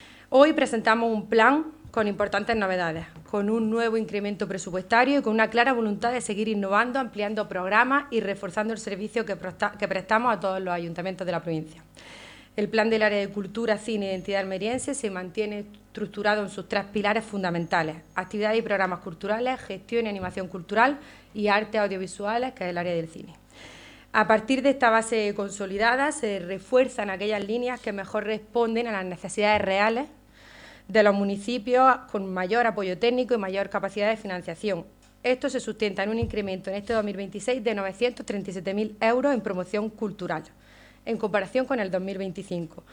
02-02-PLENO-CULTURA-ALMUDENA-MORALES.mp3